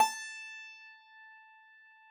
53j-pno17-A3.wav